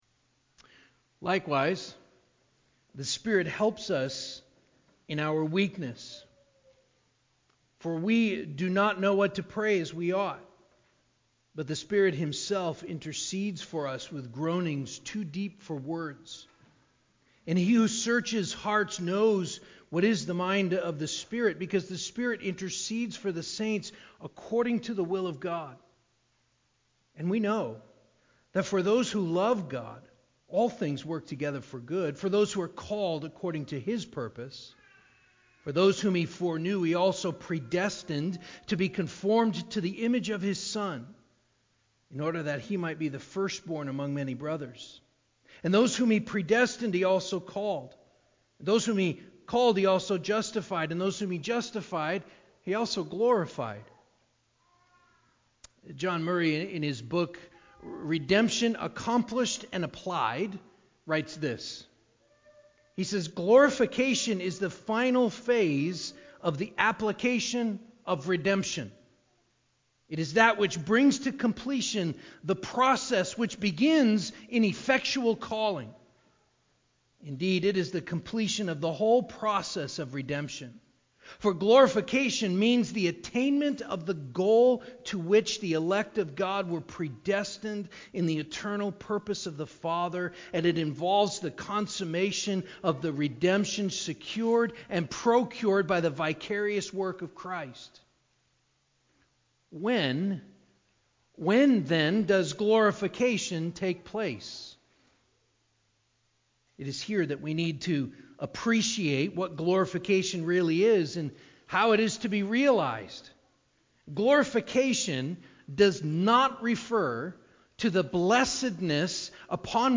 10.9.22-sermon-CD.mp3